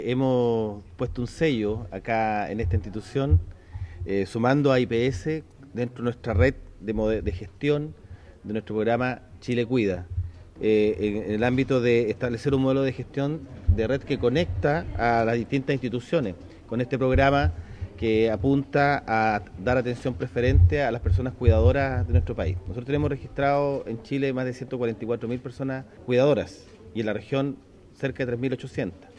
El delegado presidencial, Jorge Alvial, precisó que en la región de Los Ríos hay cerca de 3.800 personas cuidadoras.